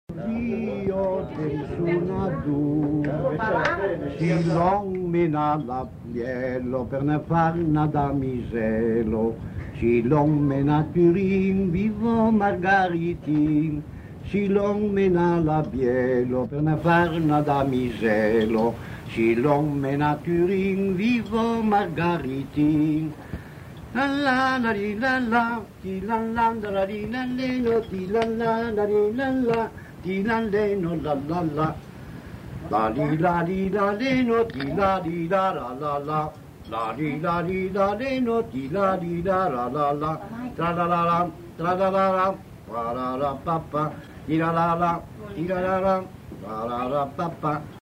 Aire culturelle : Val Varaita
Lieu : Bellino
Genre : chant
Effectif : 1
Type de voix : voix d'homme
Production du son : fredonné ; chanté